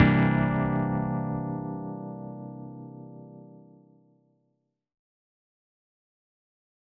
004_Dom7add9.L.wav